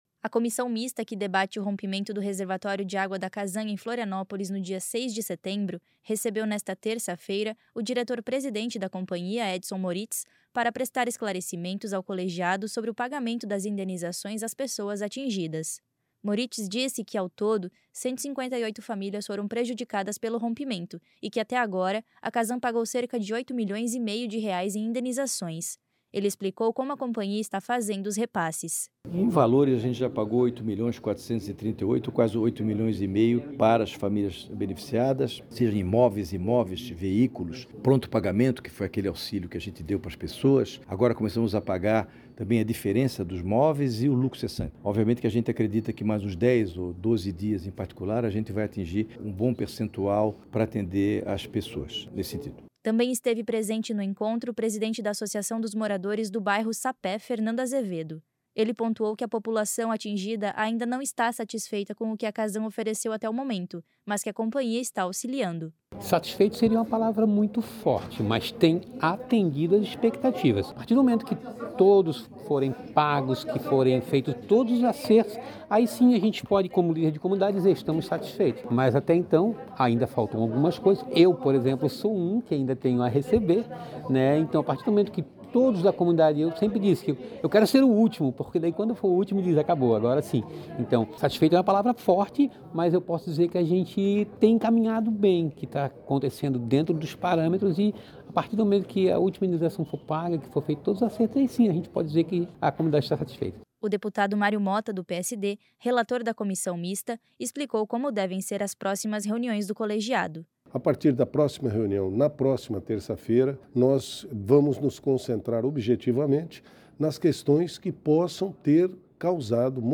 Entrevistas com:
- deputado Mário Motta (PSD), relator da comissão mista.